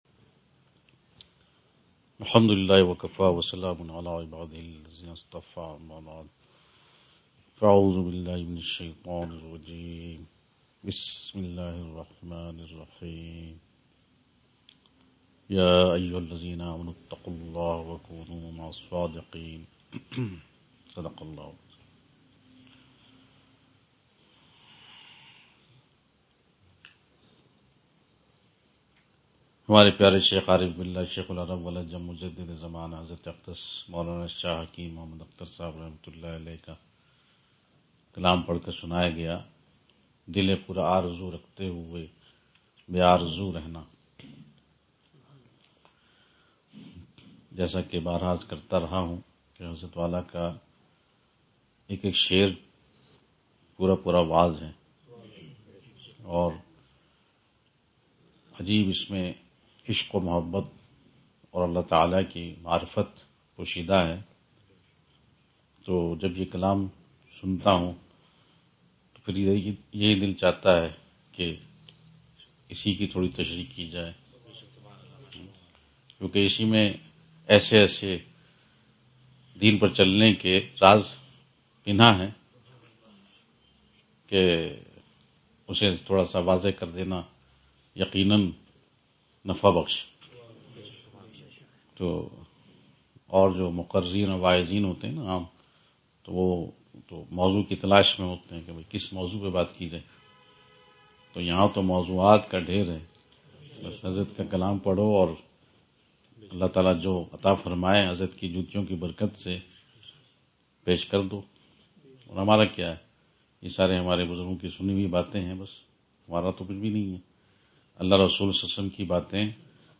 Majlis of January 14